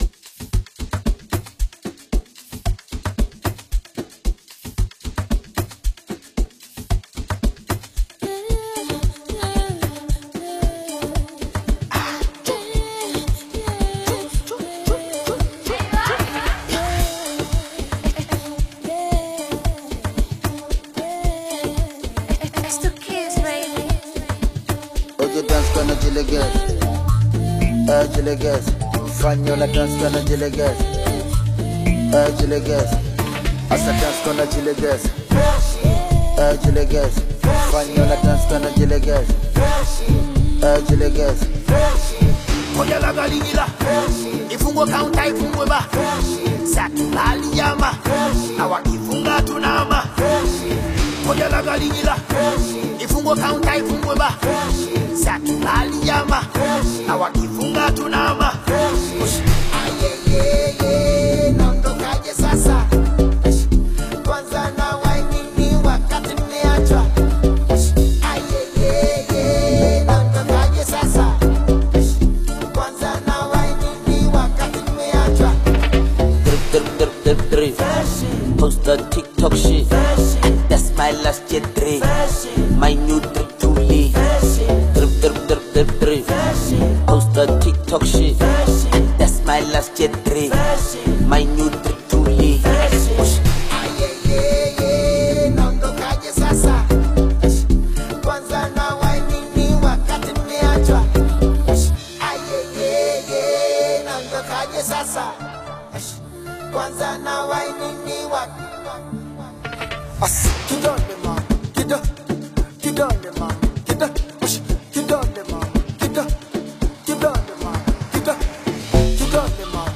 Amapiano influenced record
African Music